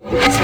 VEC3 Reverse FX
VEC3 FX Reverse 15.wav